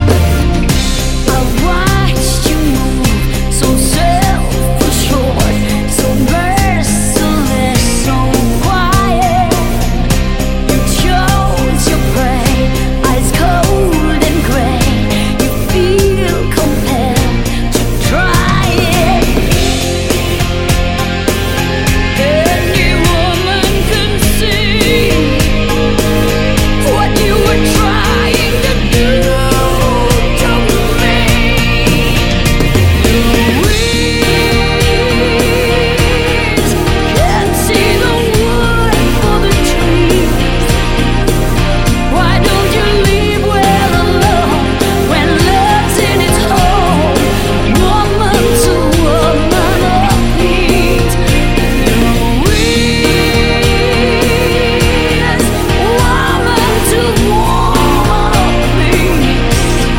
Рок